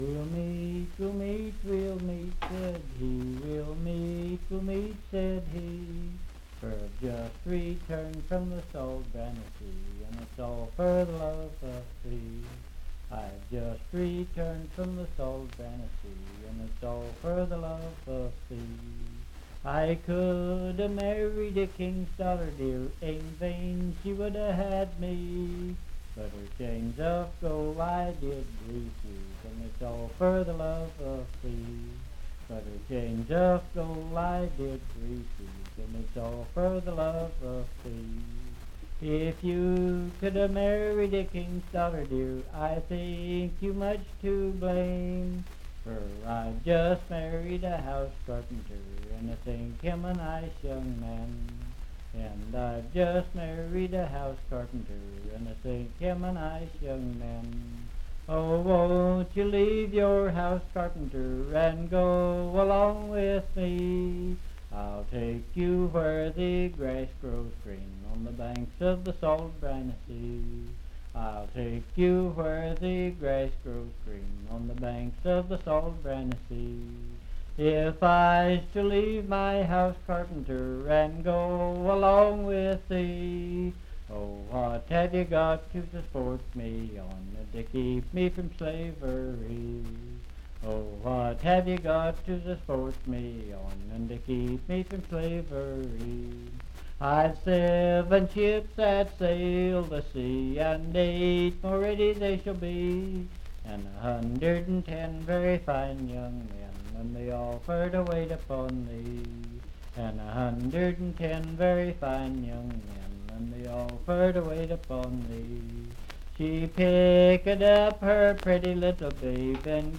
Unaccompanied vocal music
Voice (sung)
Pendleton County (W. Va.)